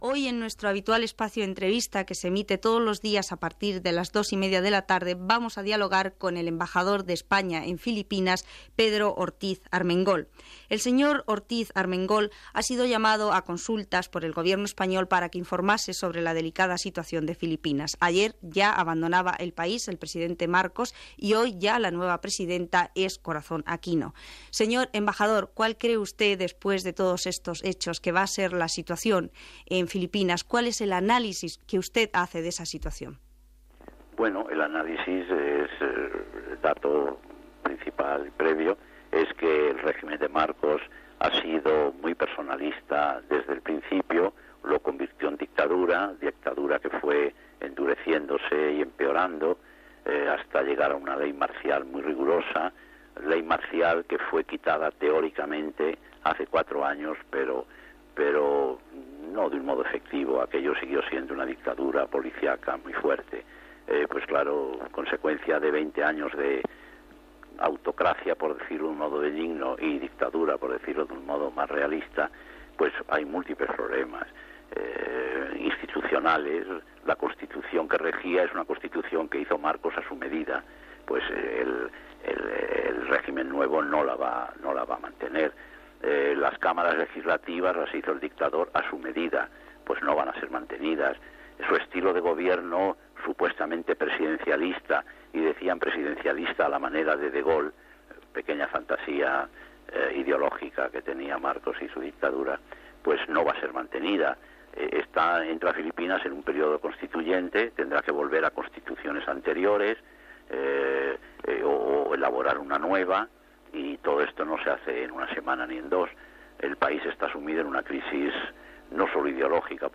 Entrevista
Entrevista a l'ambaixador espanyol a Filipines Pedro Ruiz Armengol, una vegada que ha acabat la dictadura de Ferdinand Marcos en aquell país.
Informatiu